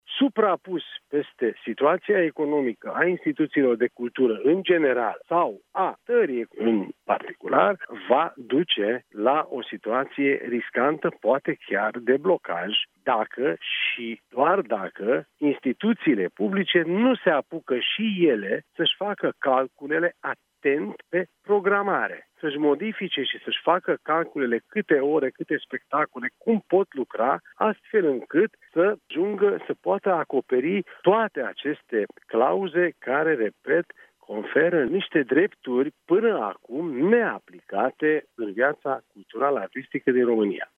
Ministrul Culturii, Demeter Andráș , la Europa FM.